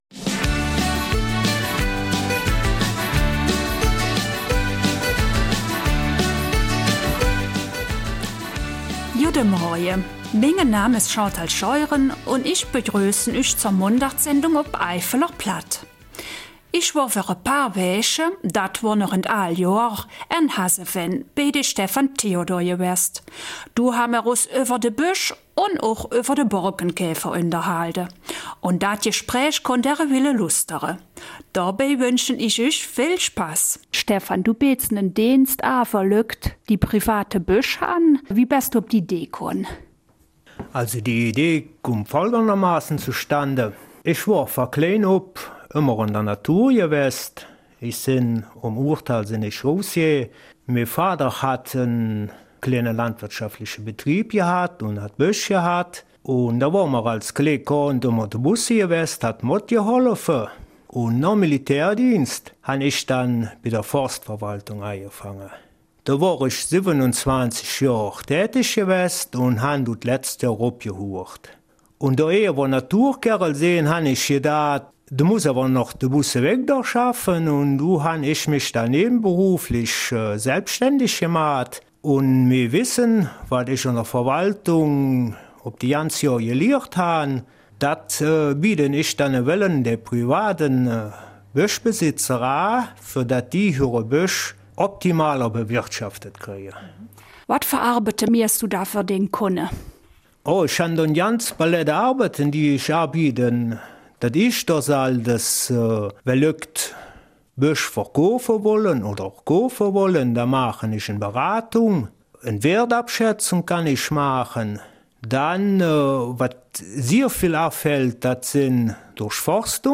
Eifeler Mundart: Privatwaldberatung